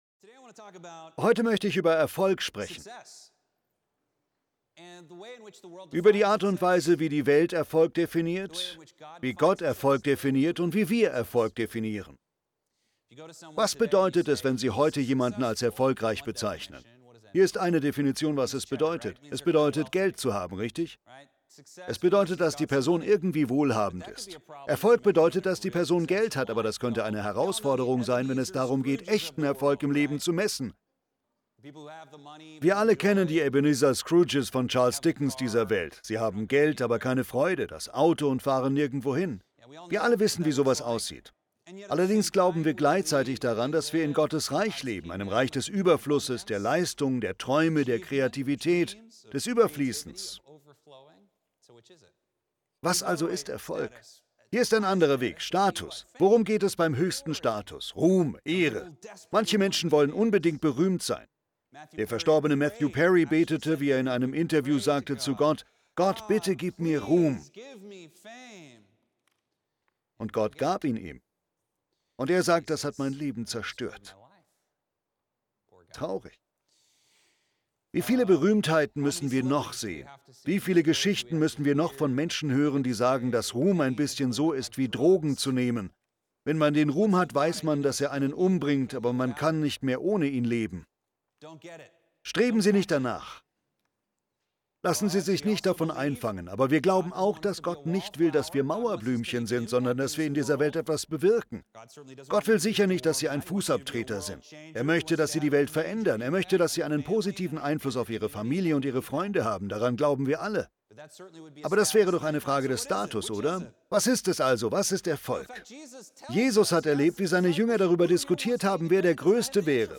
Gottesdienste Archiv - Hour of Power - die beste Stunde im Fernsehen